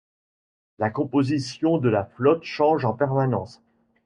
Pronounced as (IPA)
/pɛʁ.ma.nɑ̃s/